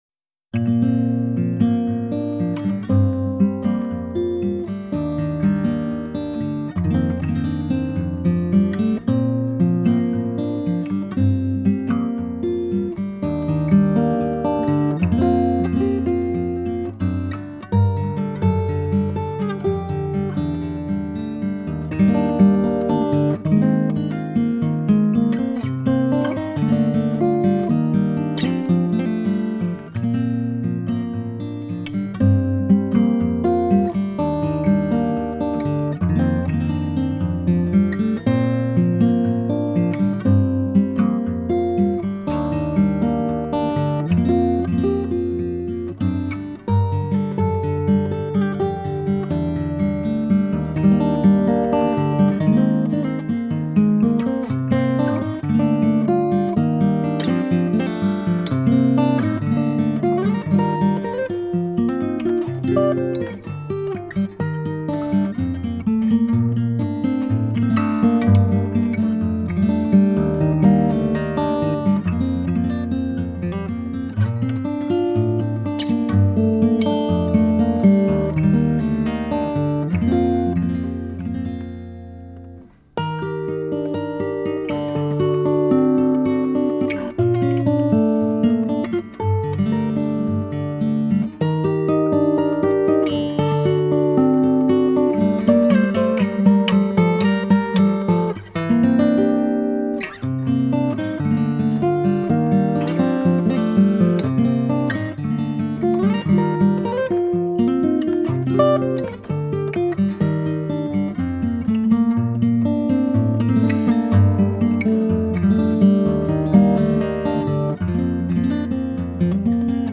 他把自己丰富的 音乐经验融入到演奏,创作以及吉他的教学工作中!